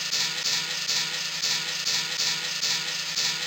METAL BACK-R.wav